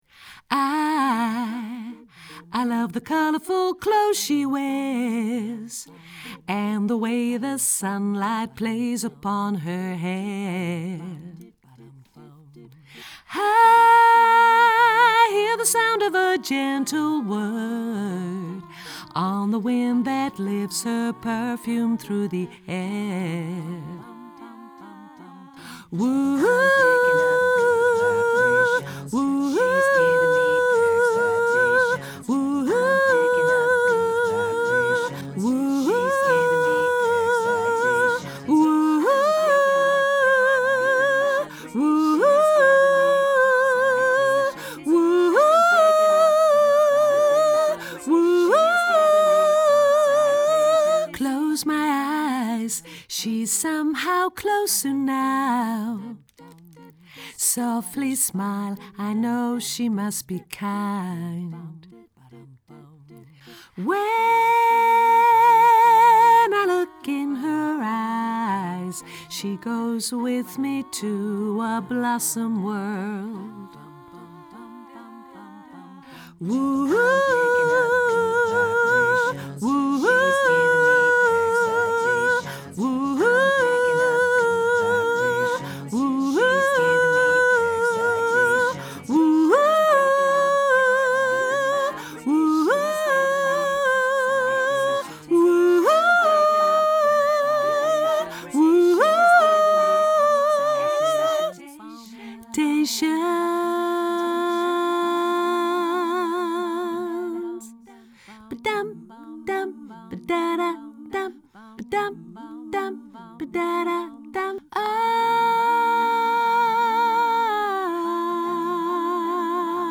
hoog sopraan